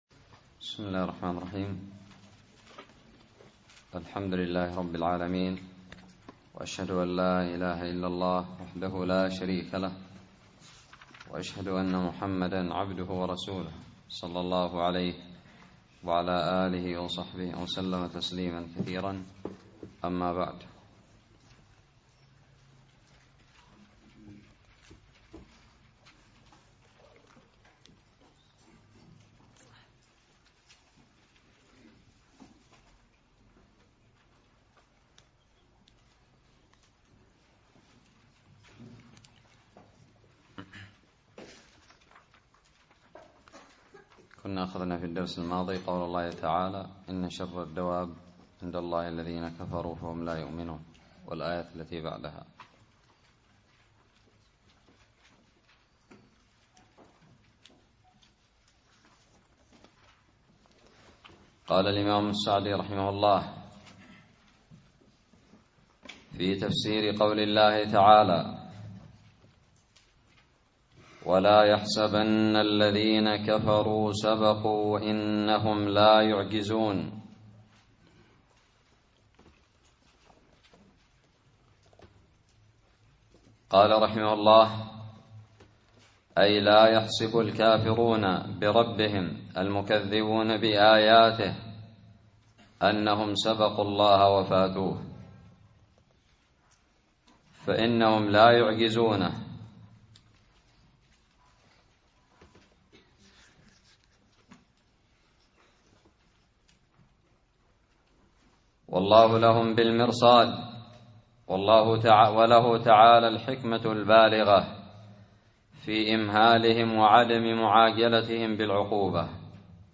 الدرس الحادي والعشرون من تفسير سورة الأنفال
ألقيت بدار الحديث السلفية للعلوم الشرعية بالضالع